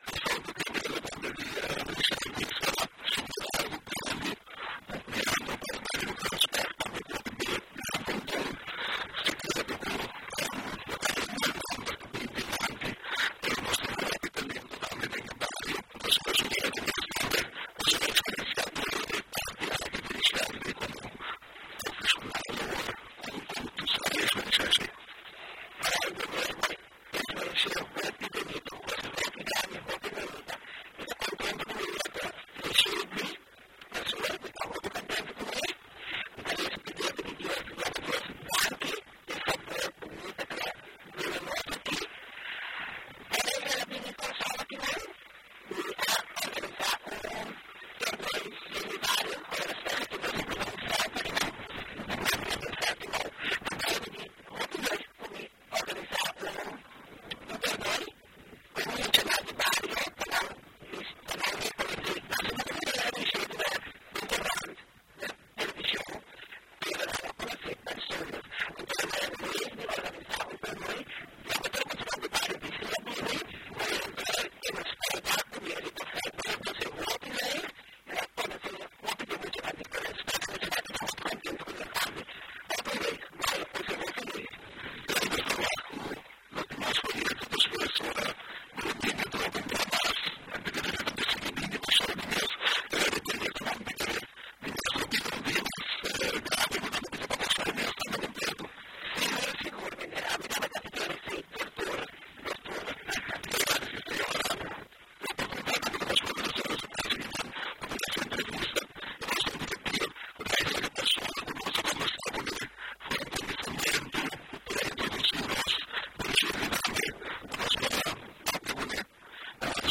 Entrevista ku prospekto di Ajax Riechedly Bazoer